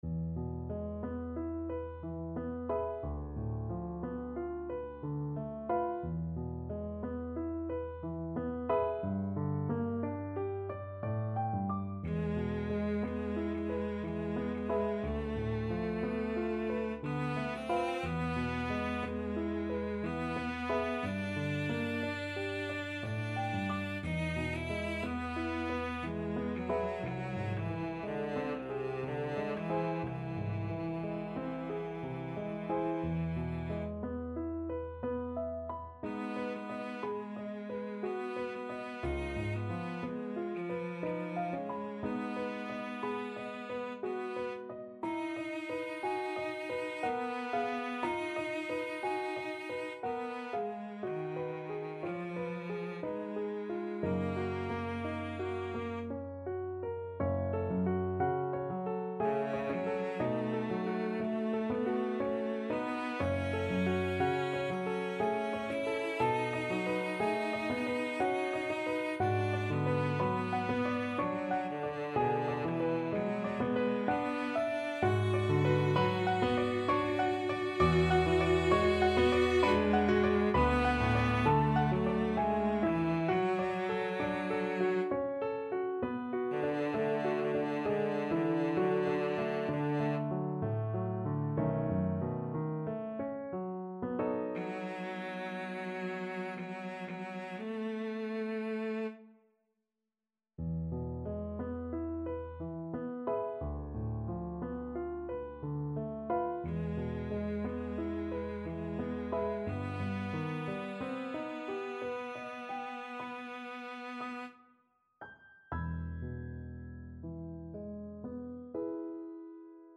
Cello version
3/4 (View more 3/4 Music)
Andante ma non troppo =60
Classical (View more Classical Cello Music)